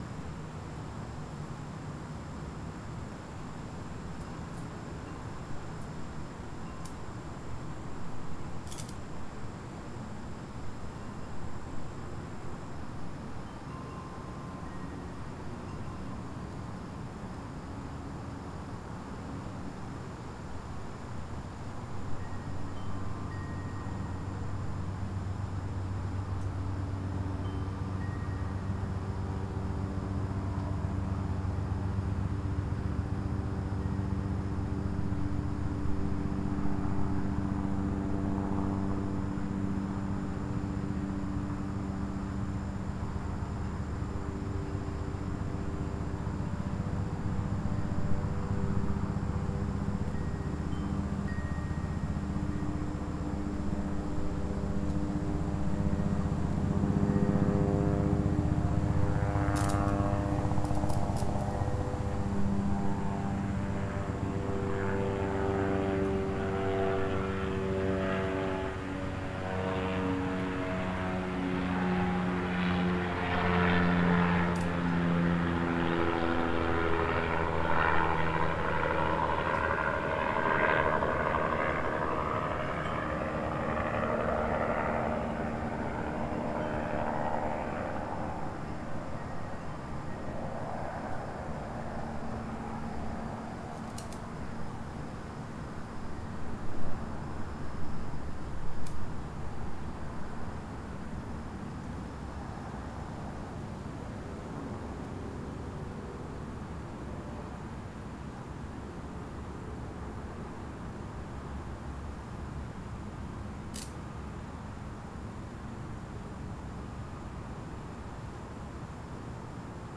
Sample #7: Helicopter (02:11) (11.5MB/file).
B-format files for experimental mic.
Helicopter passing over my front yard on April 29, 2008.